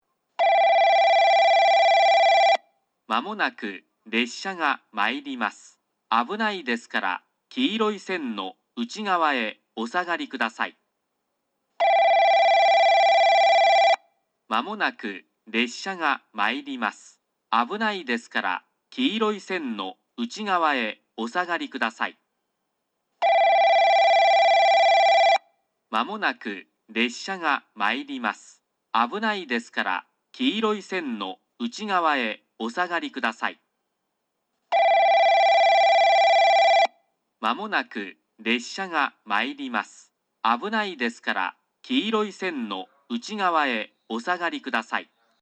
1、3番のりば接近放送　男声
スピーカーはJRホームがJVCラインアレイやカンノボックス型、おれんじ鉄道はTOAラッパ型です。